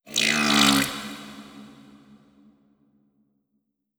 khloCritter_Female33-Verb.wav